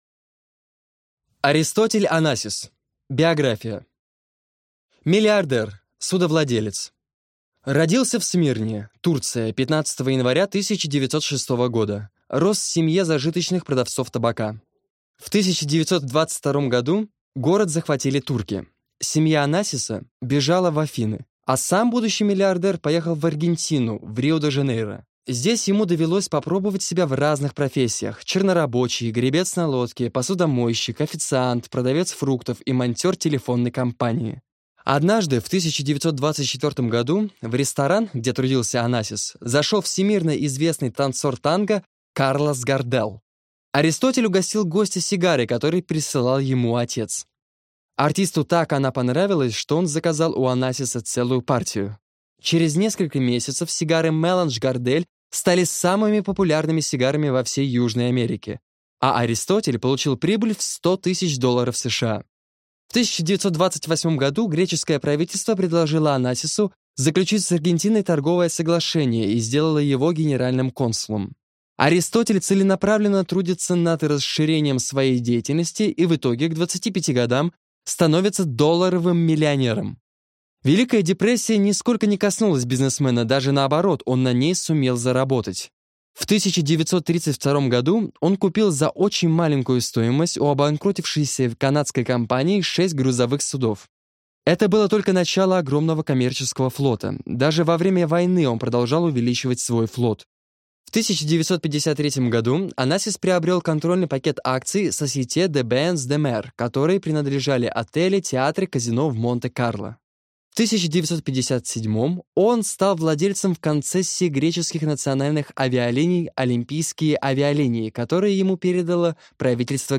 Аудиокнига Бизнес-цитаты | Библиотека аудиокниг